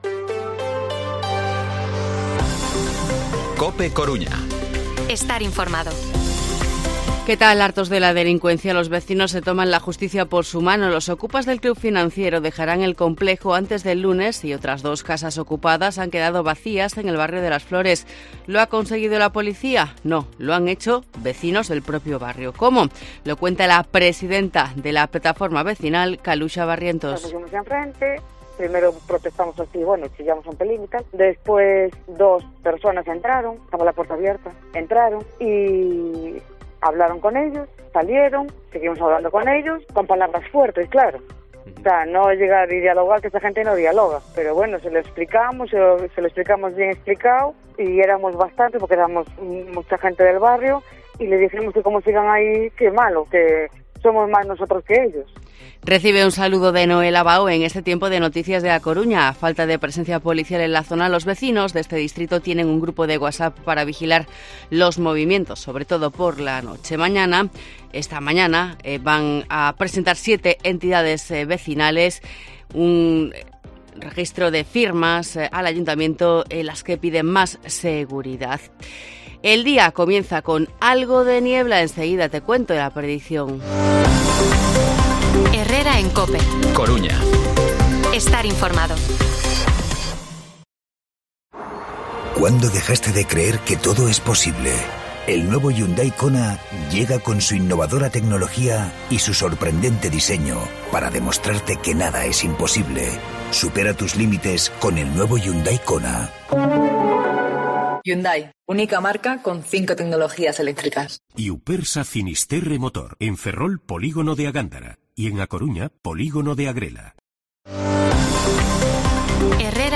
Informativo Herrera en COPE Coruña viernes, 25 de agosto de 2023 8:24-8:29